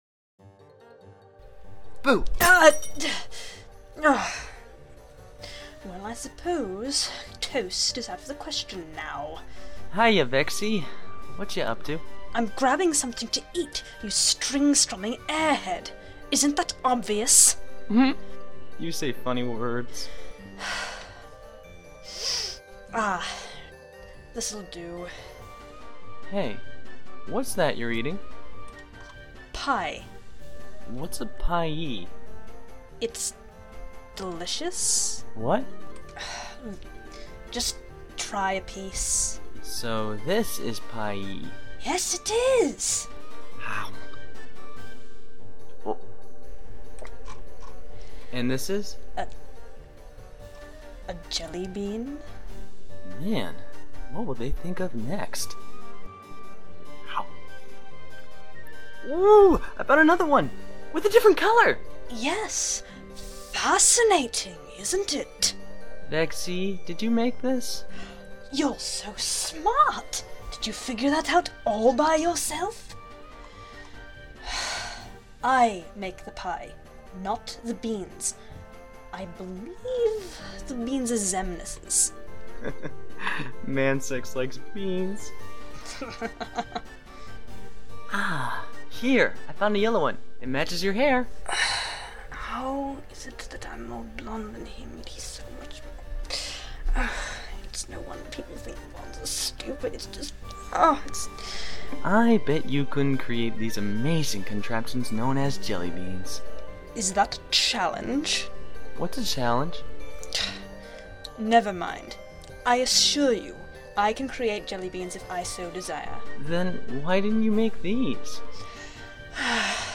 Short Dramas